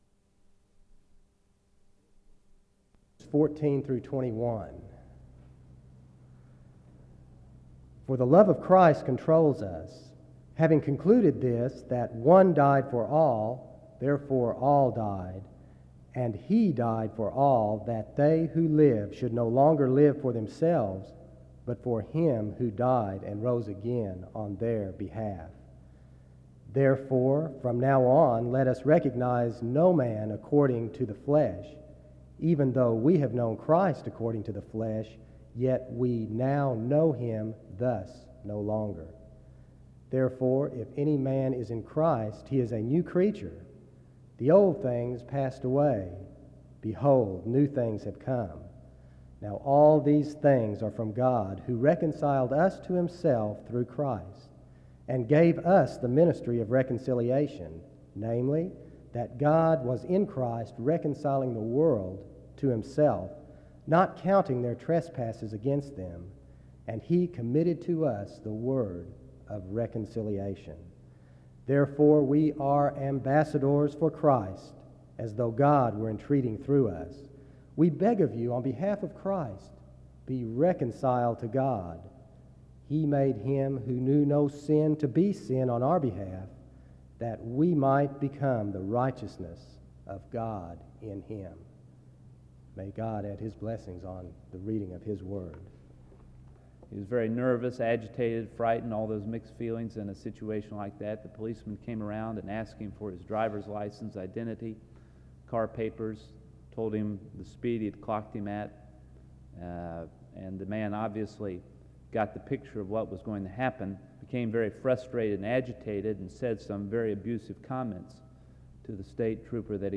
The audio was transferred from audio cassette.
SEBTS Chapel and Special Event Recordings SEBTS Chapel and Special Event Recordings